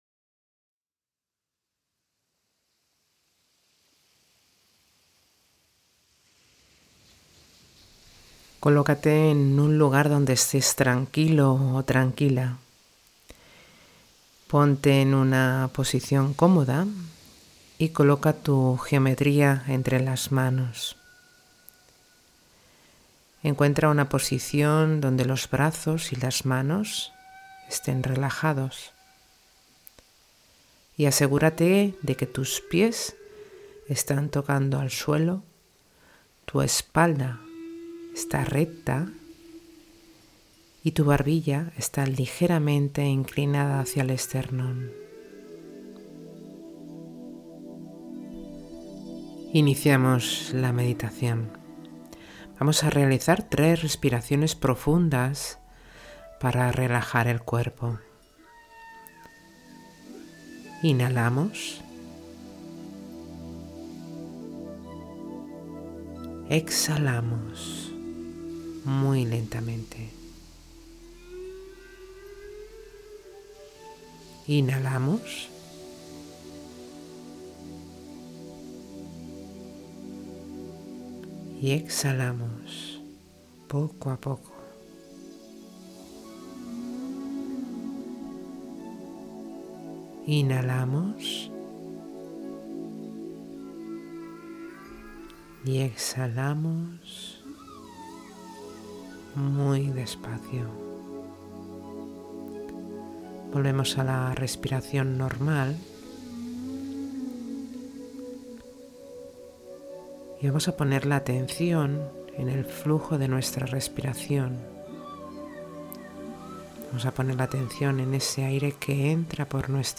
Meditación Básica Icosaedro Truncado